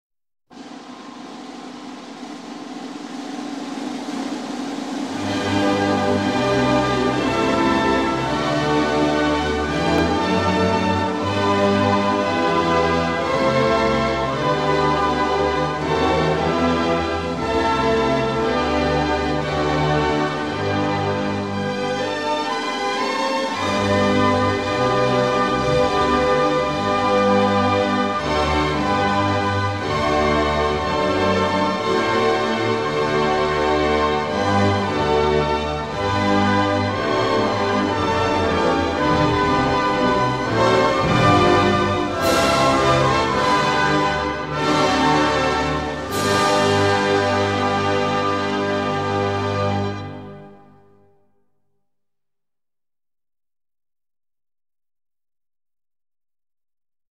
Инструментальная версия гимна Лихтенштейна